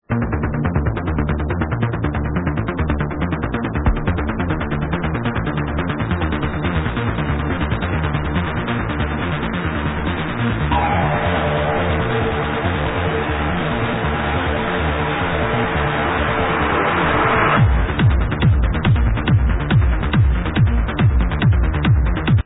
HardTrance Track